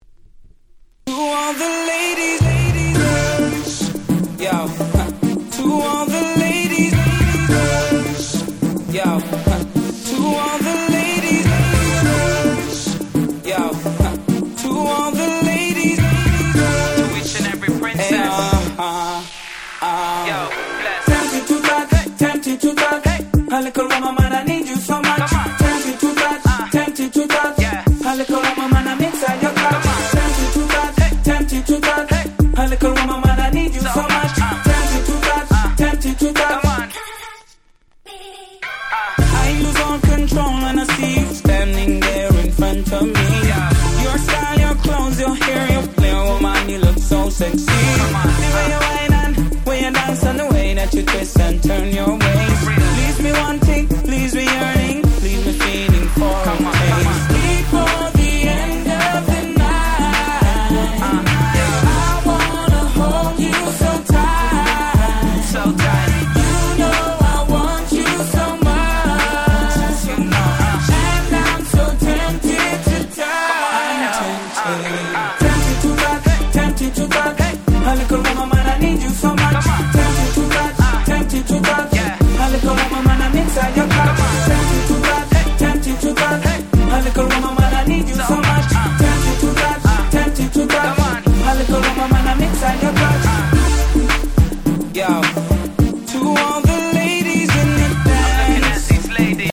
04' Super Hit Soca !!